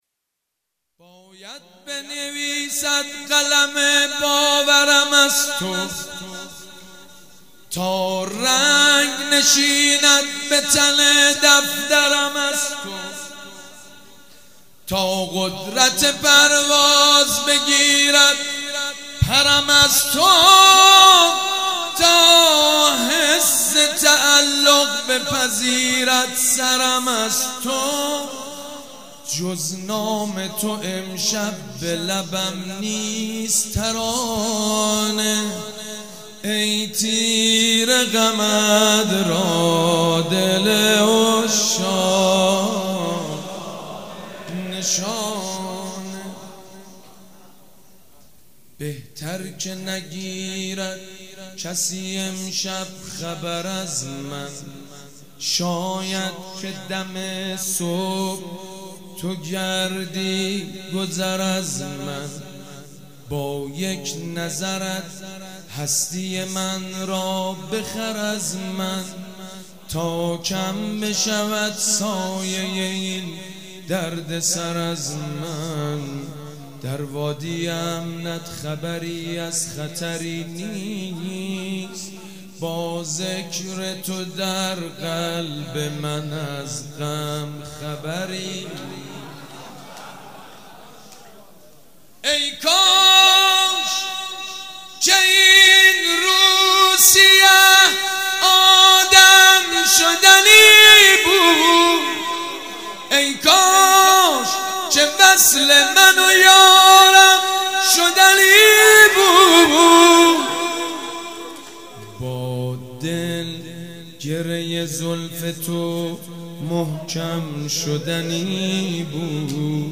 مدح: باید بنویسد قلم باورم از تو